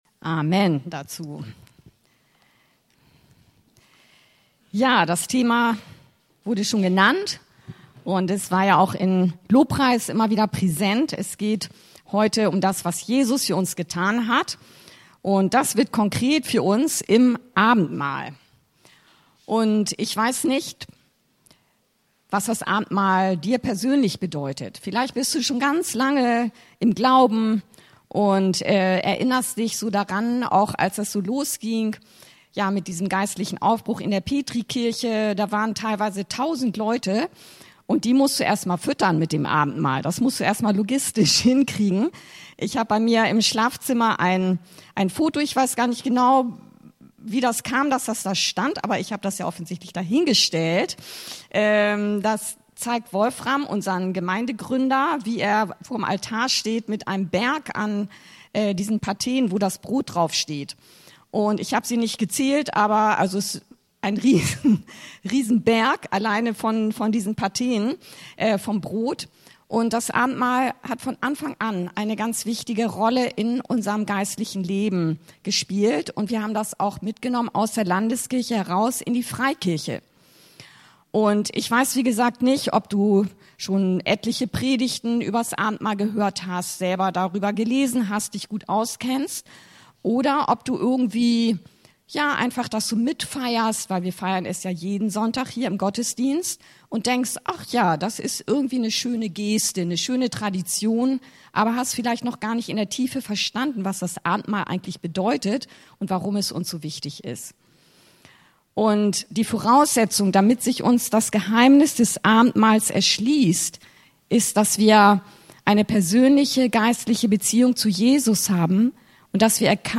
Abendmahl- Geschenk Gottes! ~ Anskar-Kirche Hamburg- Predigten Podcast